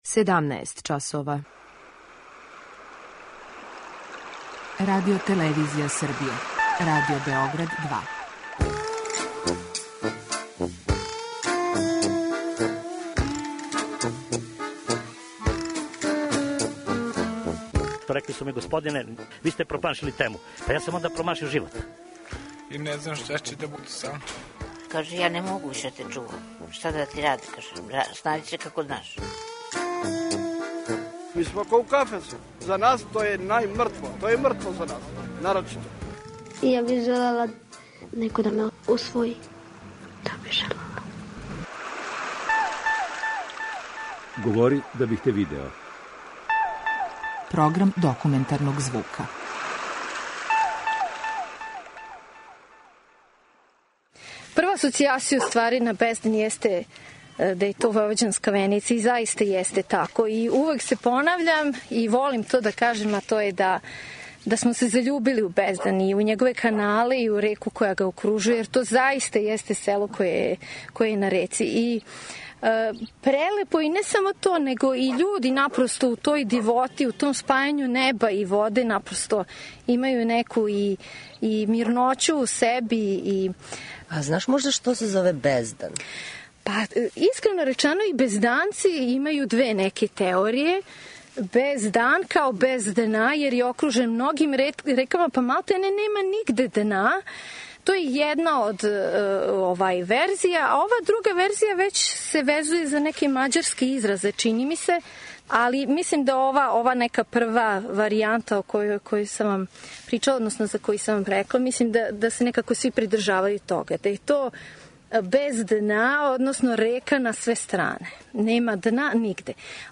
Документарни програм: Феминисткиње у пољу